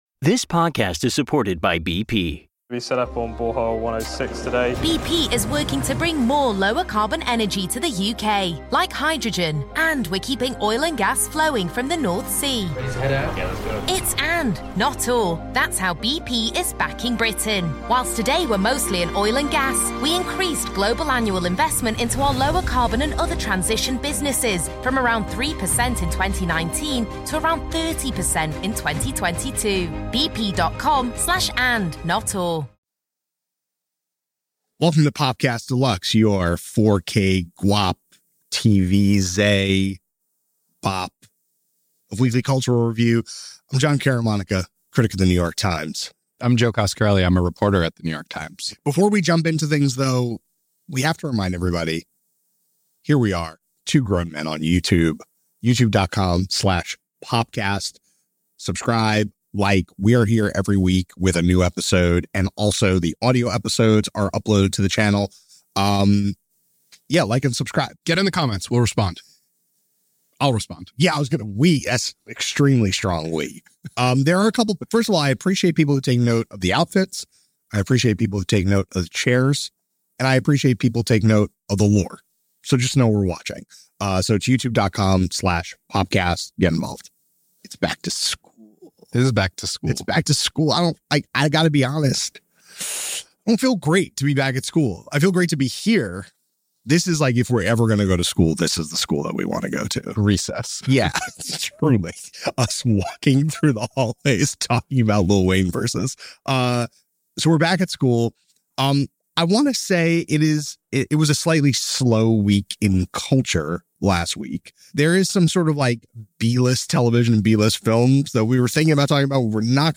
A discussion on the state of the genre and where it may be heading.